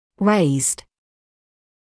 [reɪzd][рэйзд]приподнятый; повышенный; выпуклый (о колонии бактерий); 2-я и 3-я форма от to raise